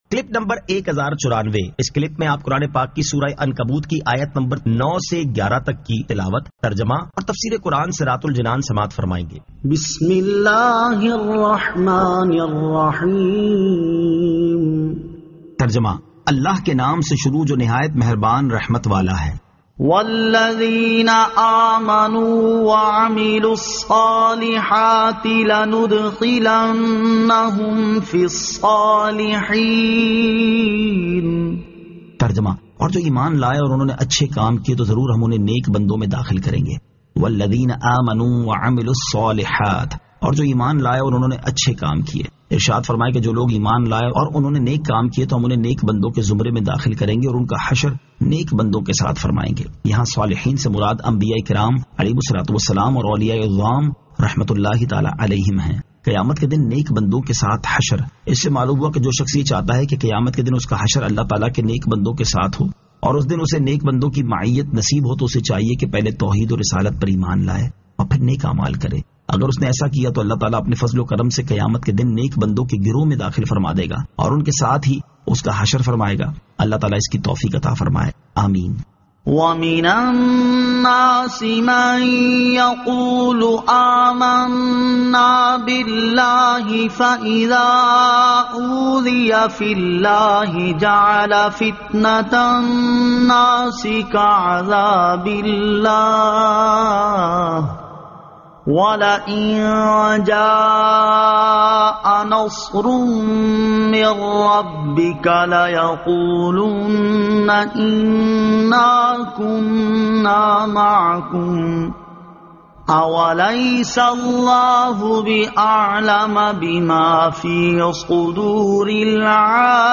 Surah Al-Ankabut 09 To 11 Tilawat , Tarjama , Tafseer